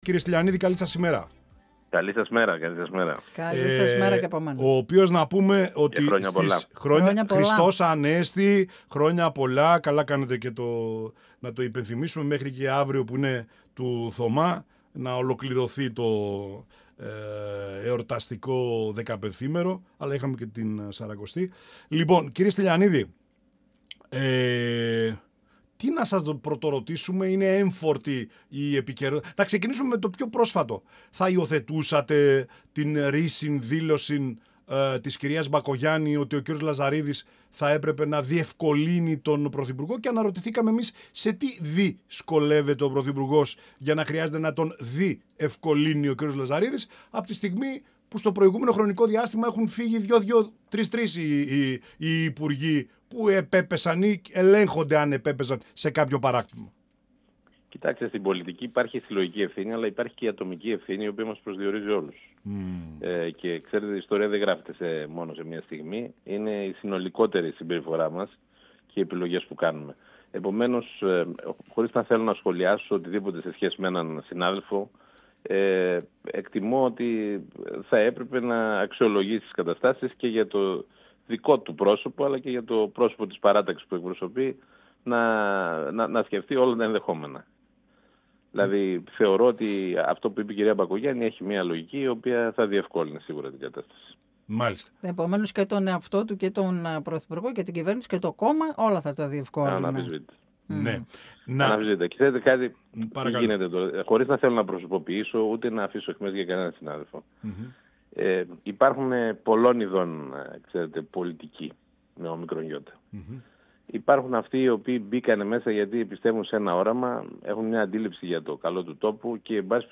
Στο θέμα της άρσεως ασυλίας των βουλευτών της ΝΔ για το σκάνδαλοτου ΟΠΕΚΕΠΕ αλλά και το θέμα Λαζαρίδη και τις παρενέργειές τουαναφέρθηκε ο π. Υπουργός και Βουλευτής Ροδόπης της ΝΔ ΕυριπίδηςΣτυλιανίδης, μιλώντας στην εκπομπή «Πανόραμα Επικαιρότητας» του102FM της ΕΡΤ3.